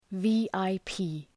Προφορά
{,vi:aı’pi:}